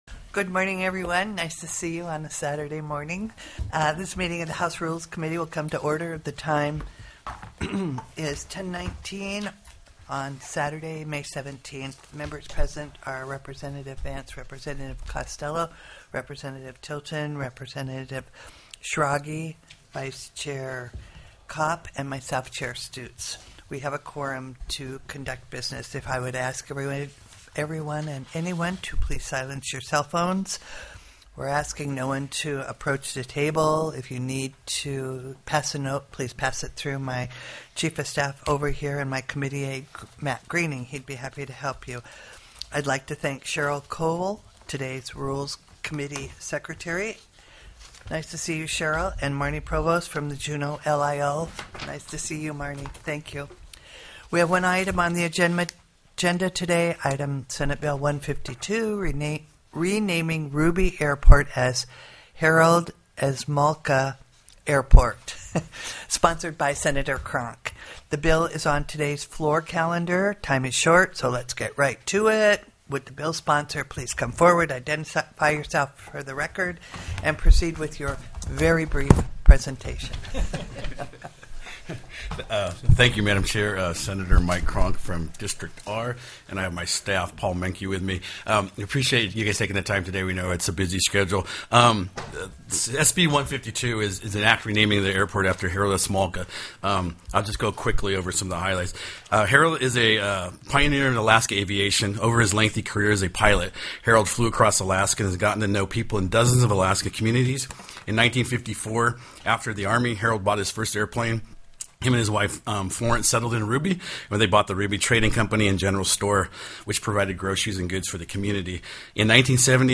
The audio recordings are captured by our records offices as the official record of the meeting and will have more accurate timestamps.
+ teleconferenced
POSITION STATEMENT:  As prime sponsor, presented SB 152.